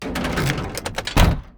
charge2.wav